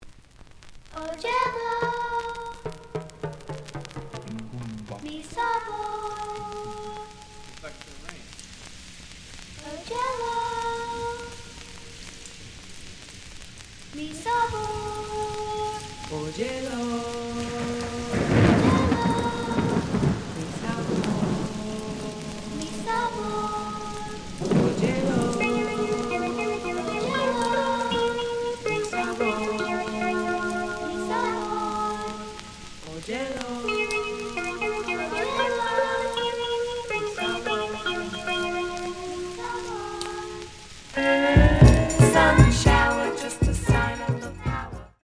The audio sample is recorded from the actual item.
●Genre: Disco
Slight edge warp.